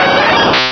pokeemerald / sound / direct_sound_samples / cries / flareon.aif
flareon.aif